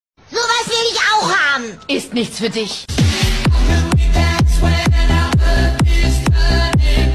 Maserati Quattroporte 4.2l V8 (Supercharger Novitec)